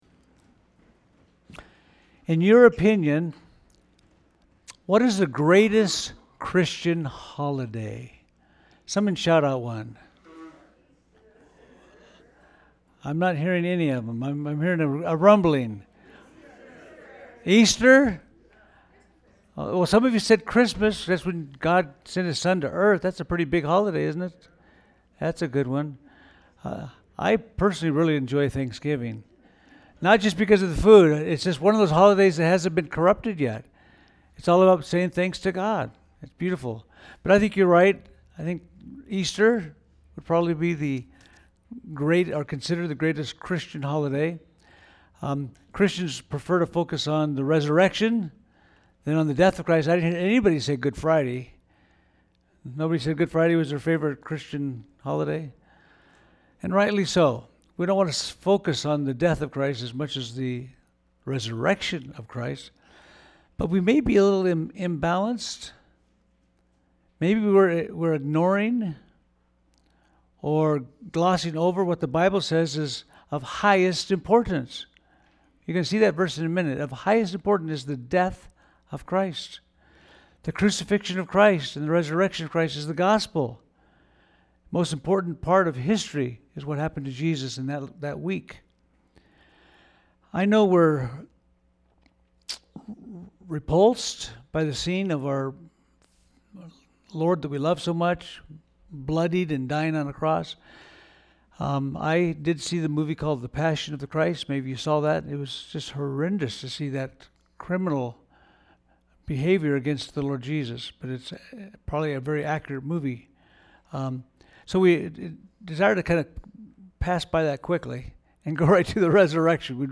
We-Preach-Christ-Crucified-Palm-Sunday-41325.mp3